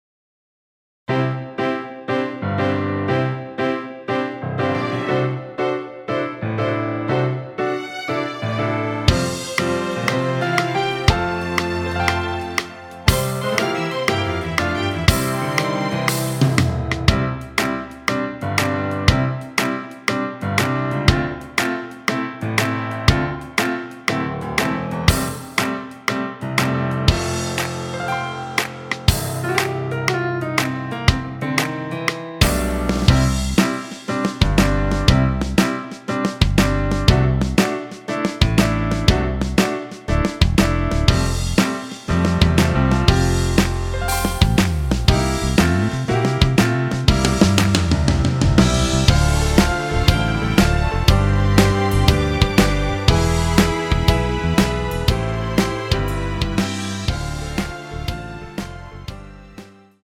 고음질 MR 다운, 코러스MR, 축가MR, 영상MR, 맞춤MR제작, 비회원7일간 무제한 다운로드